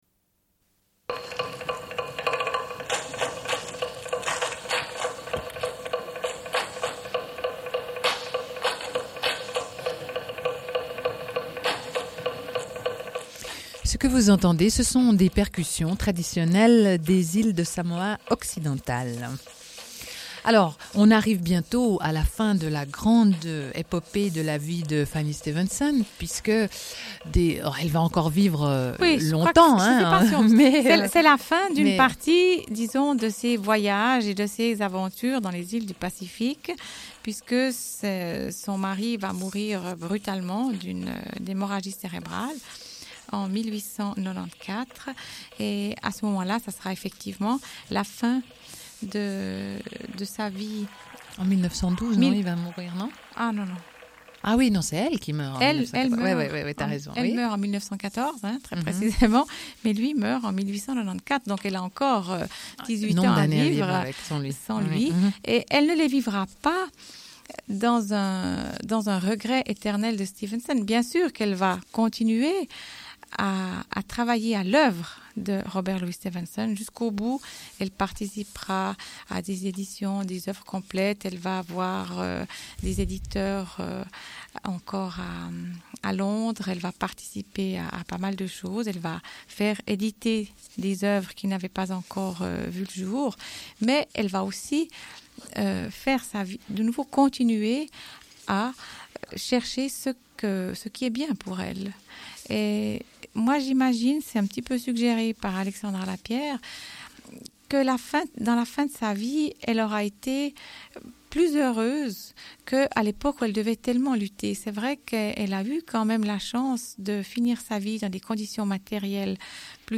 Une cassette audio, face A31:34